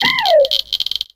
Audio / SE / Cries / TYNAMO.ogg